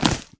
Inventory_Open_00.wav